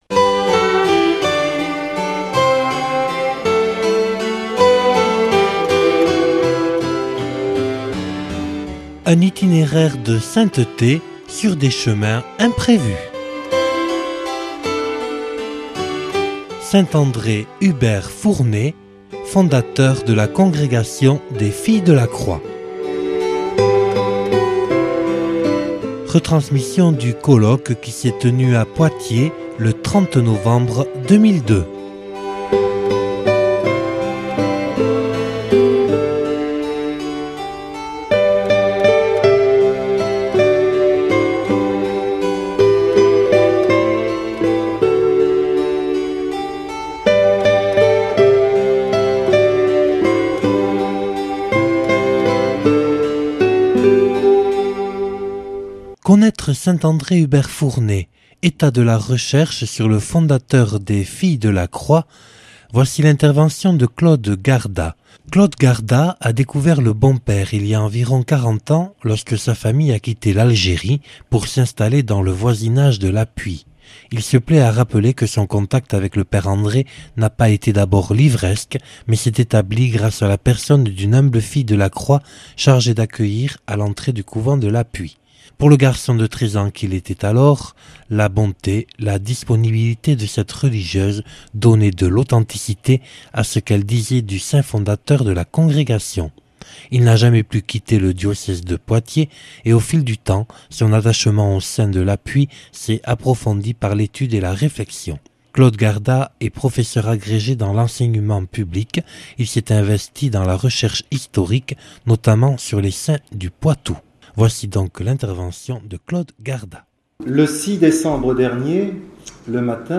professeur agrégé dans l’Enseignement Public.(Colloque enregistré le 30/11/2002 à Poitiers)